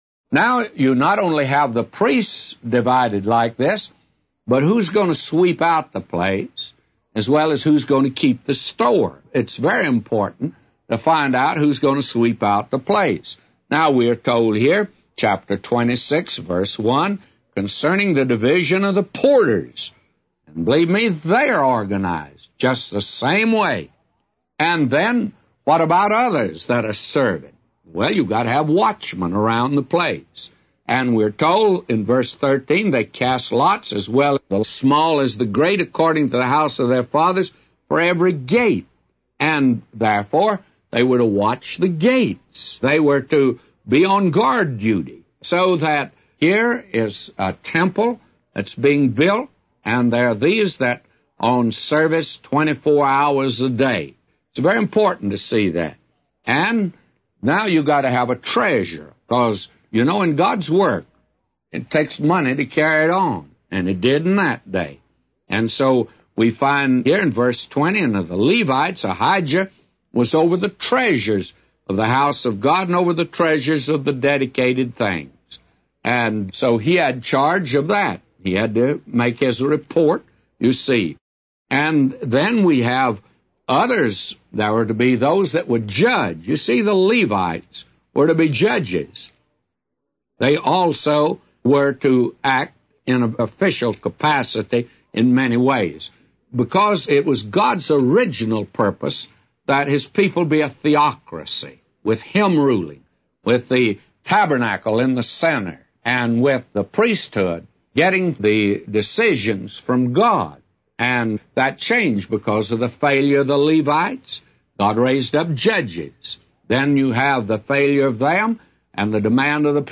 A Commentary By J Vernon MCgee For 1 Chronicles 26:1-999